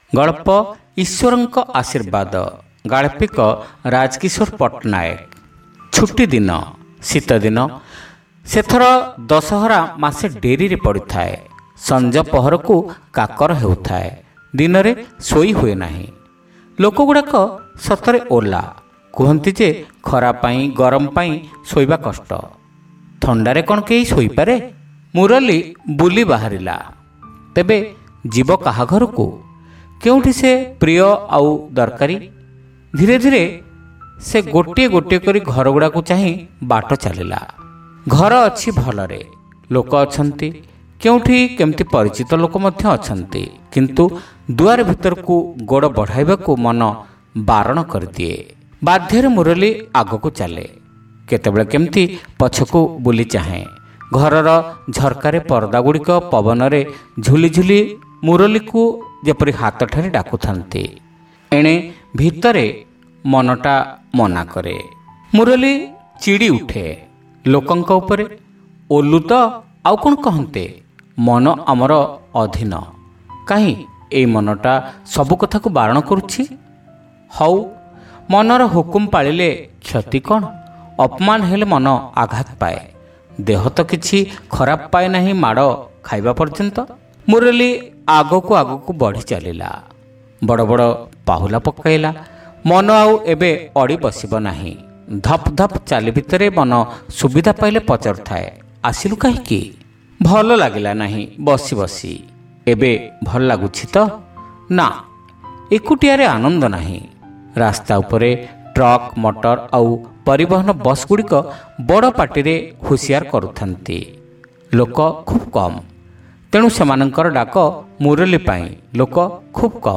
Audio Story : Ishwaranka Ashirbada